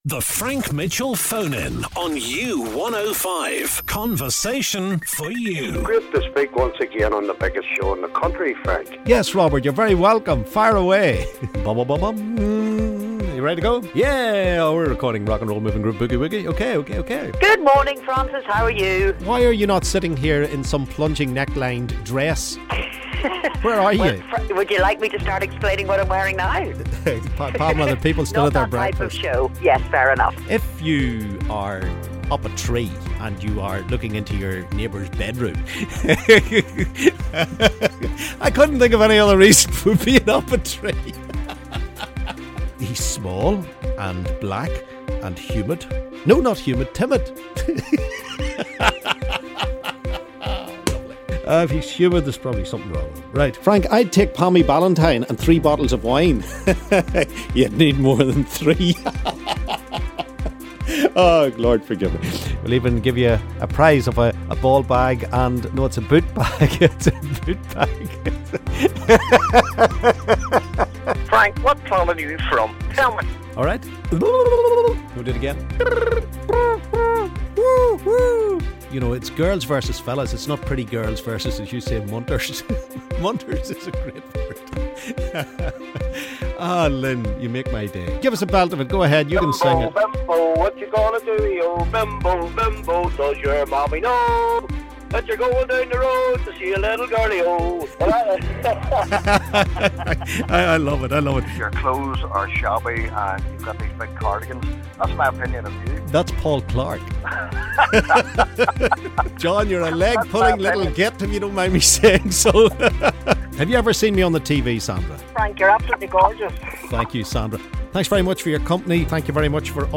BLOOPERS